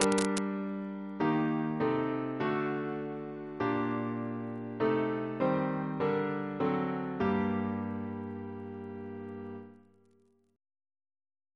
Single chant in G minor Composer: Thomas S. Dupuis (1733-1796), Organist and Composer to the Chapel Royal Reference psalters: ACB: 138; H1940: 650 692; H1982: S258; OCB: 121